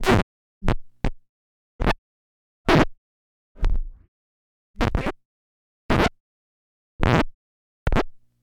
Record Needle Scratches
needle record scratches vinyl sound effect free sound royalty free Memes